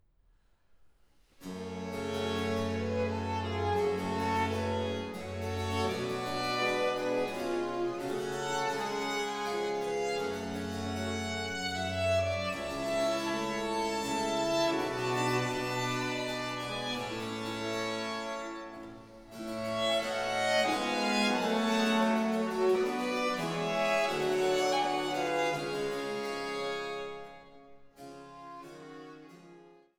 Violoncello
Theorbe
Cembalo, Polygonalspinet